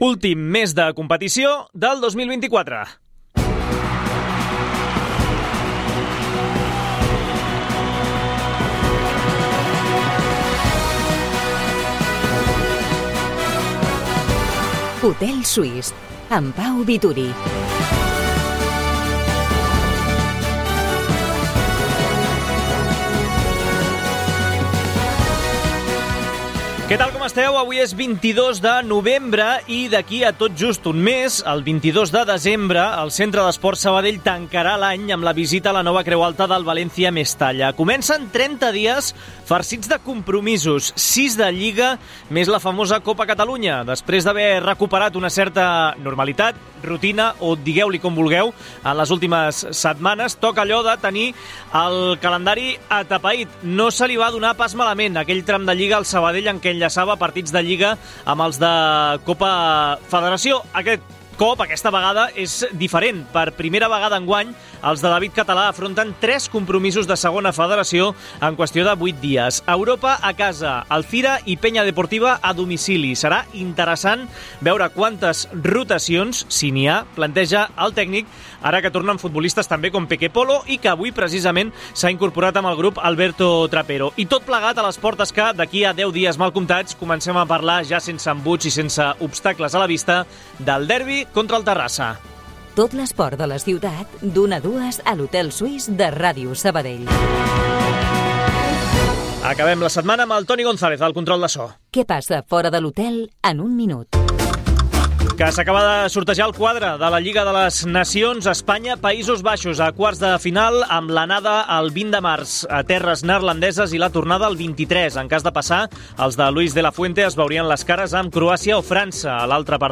La Nova Creu Alta ha acollit l'acte del Centre d'Esports Sabadell